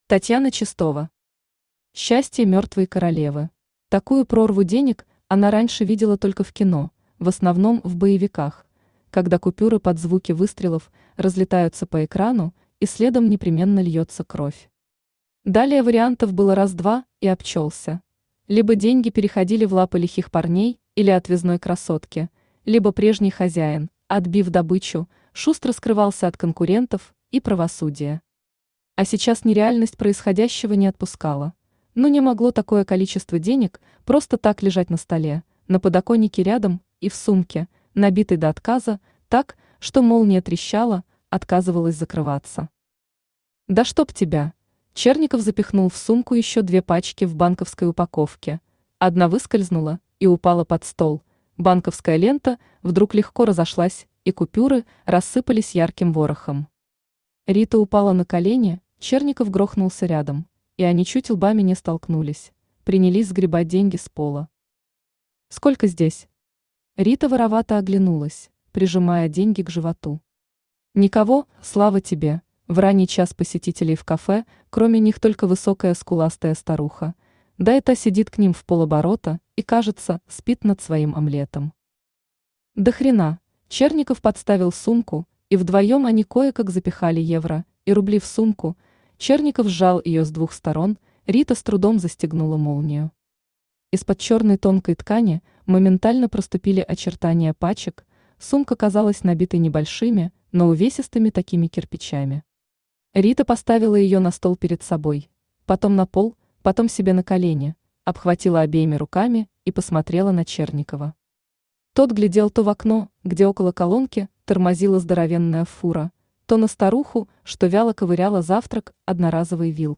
Аудиокнига Счастье мертвой королевы | Библиотека аудиокниг
Aудиокнига Счастье мертвой королевы Автор Татьяна Чистова Читает аудиокнигу Авточтец ЛитРес.